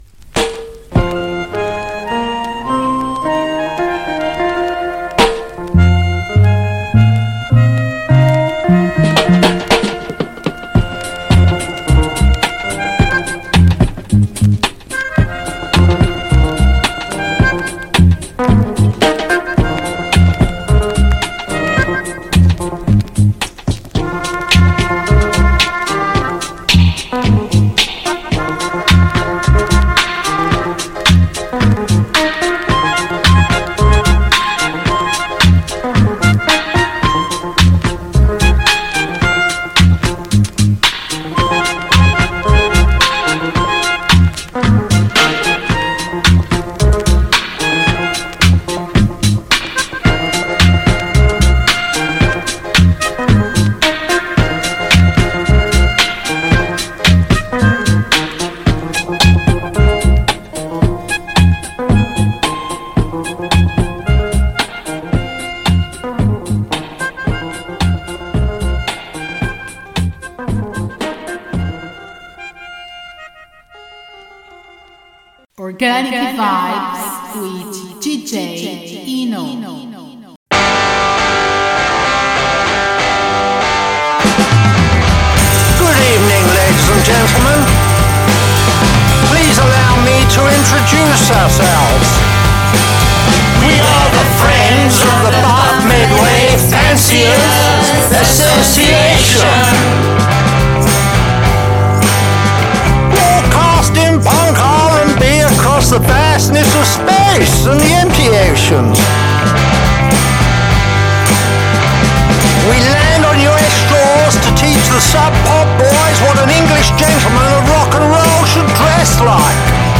Africa, Blues, Dub, Electronic, Folk, Garage Punk, Indie, Irish Traditional, Organic Vibes, Reggae, World Music